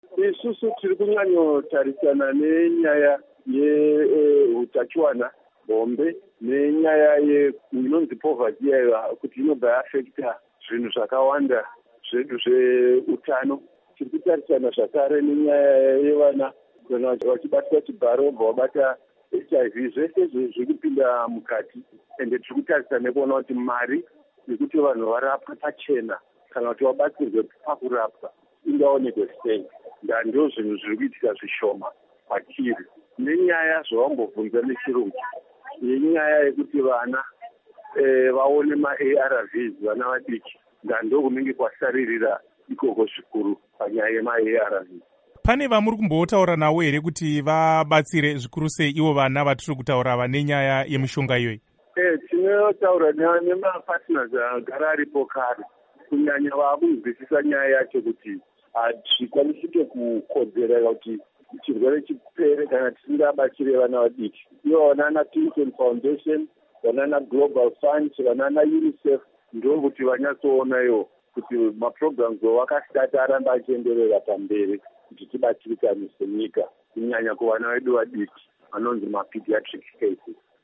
Hurukuro naDoctor David Parirenyatwa